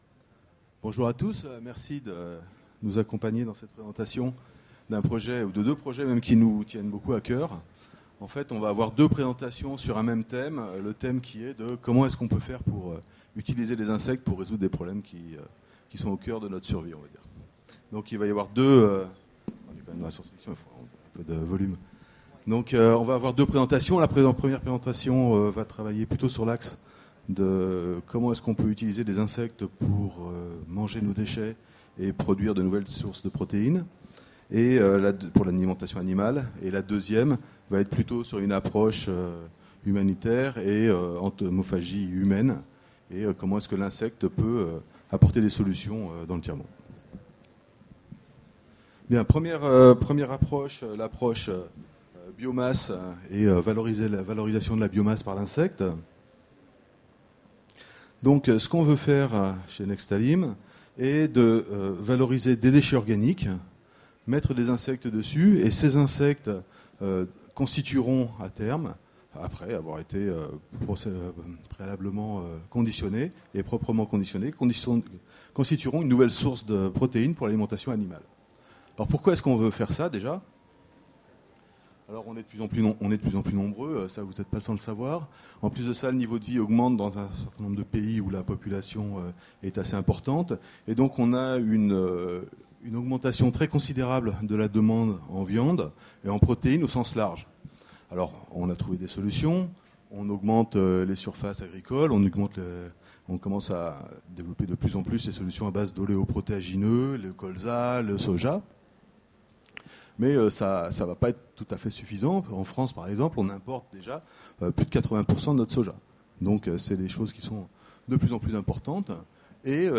Utopiales 13 : Conférence Les petites bêtes vont encore se faire manger par les grosses !